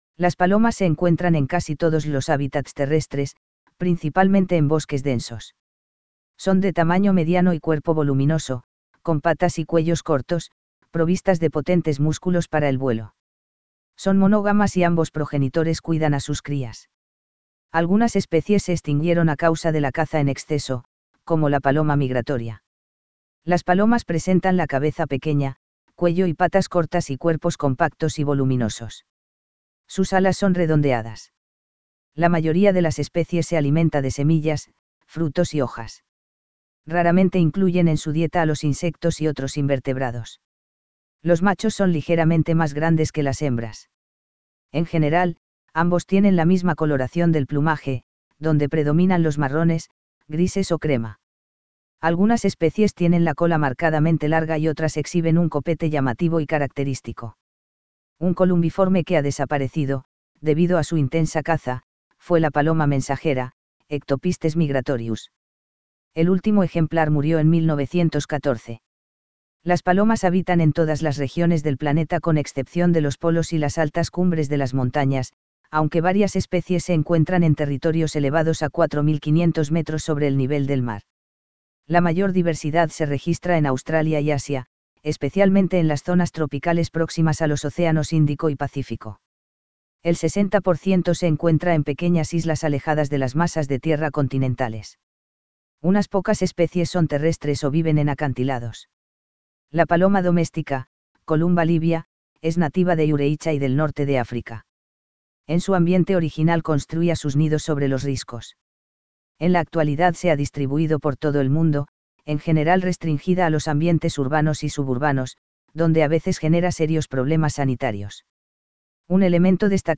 Columbiformes
Ciertas especies pueden emitir sonidos, mientras que otras son prácticamente silenciosas.
Son conocidos sus cantos graves y monótonos a modo de arrullo.